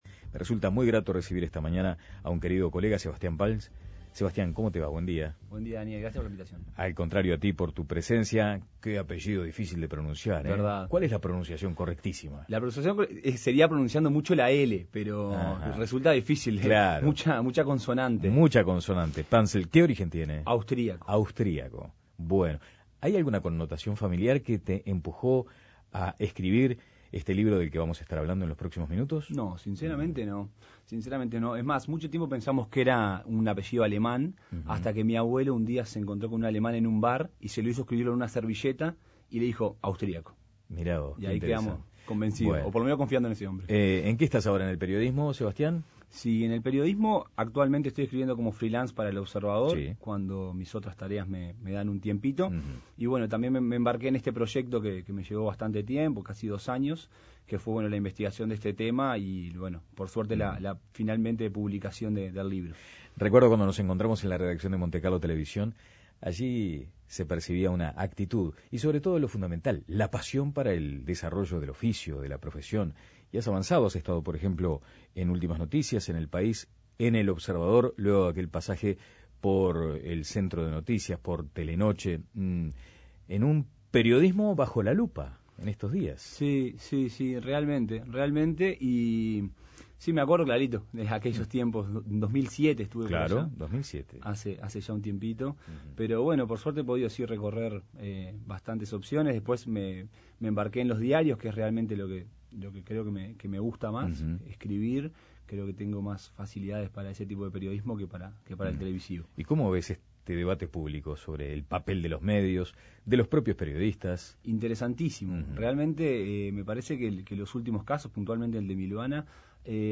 Presenta libro